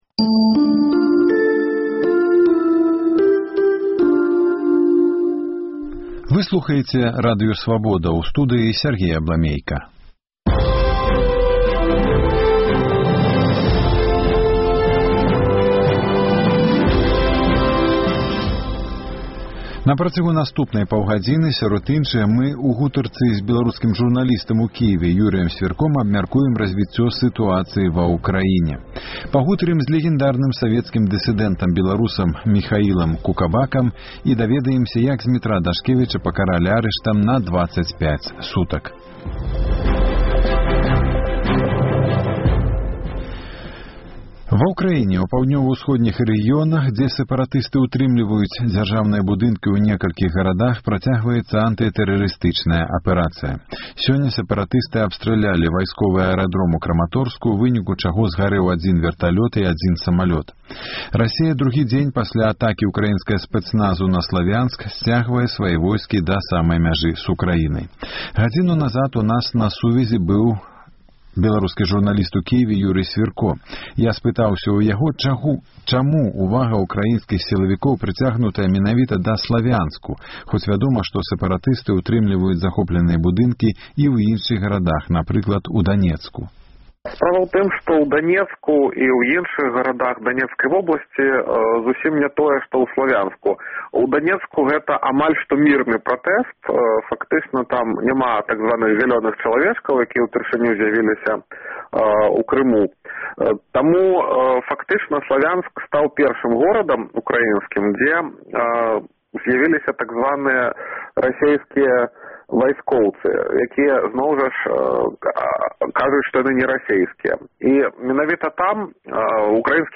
Апытаньне ў Горадні: Як вы ставіцеся да магчымасьці но